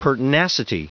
Prononciation du mot pertinacity en anglais (fichier audio)
Prononciation du mot : pertinacity